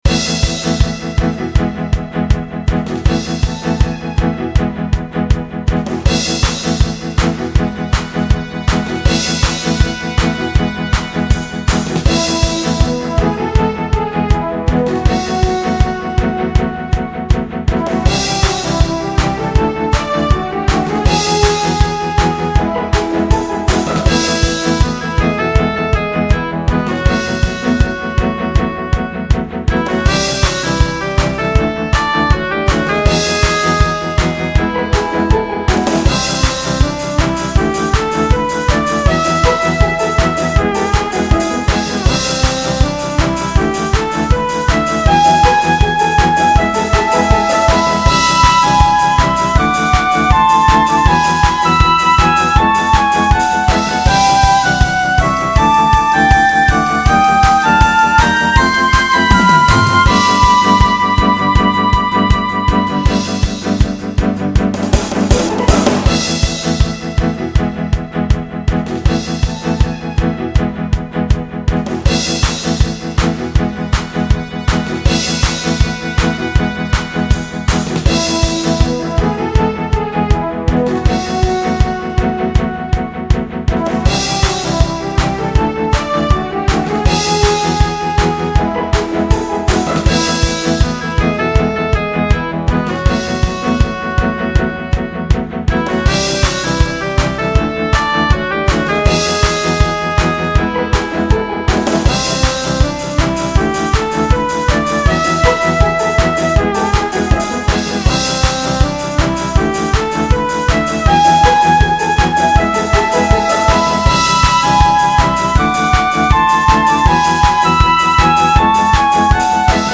ノイズも多少入ってます。
音源は基本的にハード音源のSc-8850です。
シンプルな構成を目指したので音は薄め。